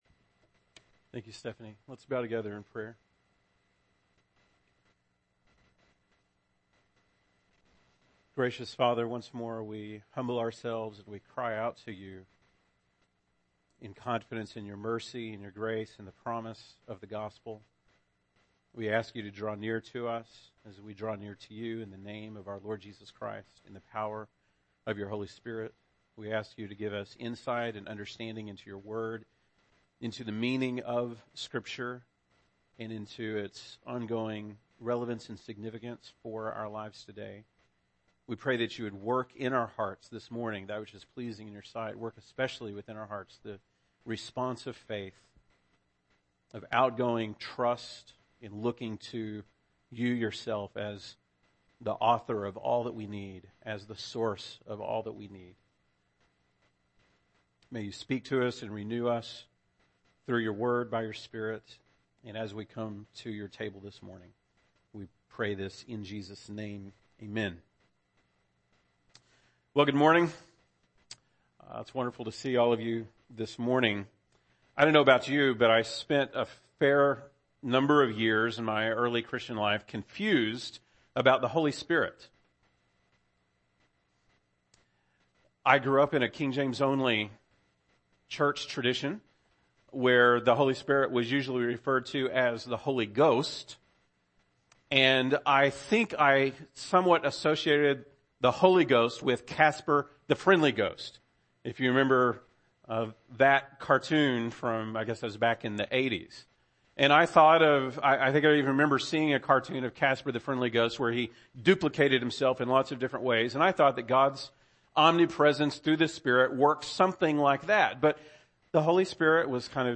June 4, 2017 ( Sunday Morning ) Bible Text